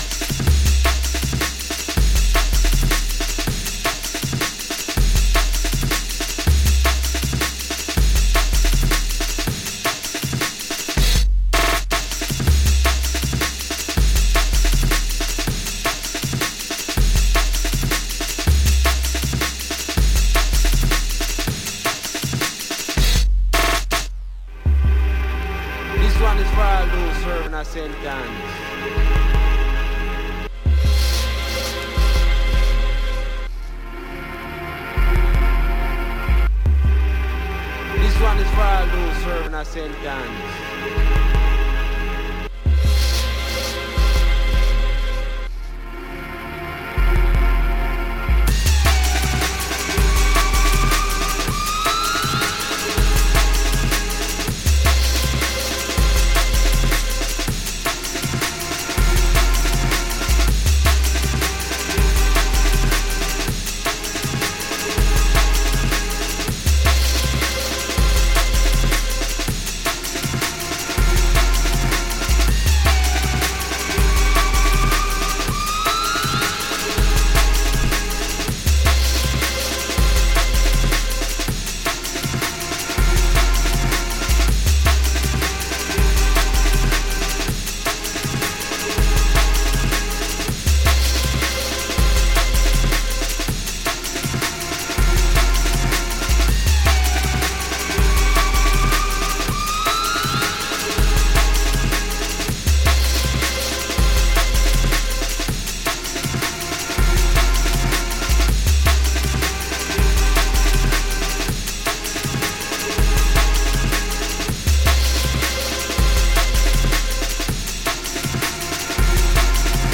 Jungle-techno essencial, a olhar novas possibilidades.